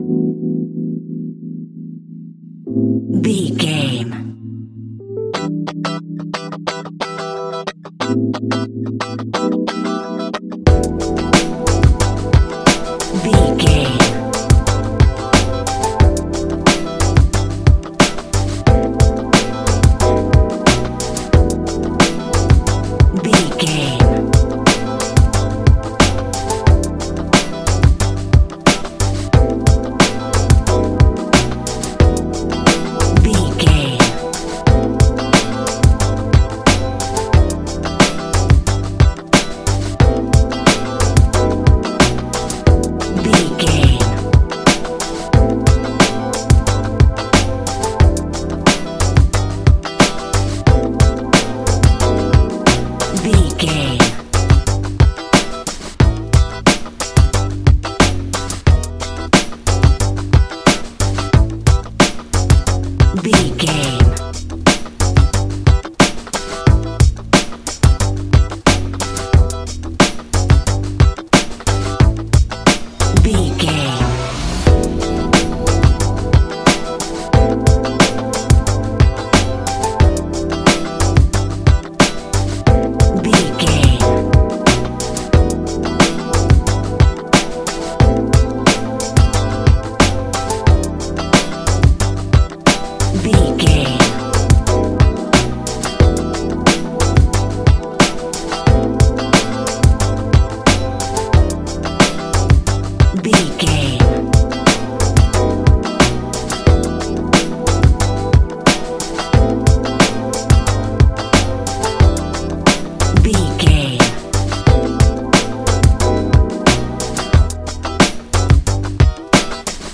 Ionian/Major
E♭
chilled
laid back
Lounge
sparse
new age
chilled electronica
ambient
atmospheric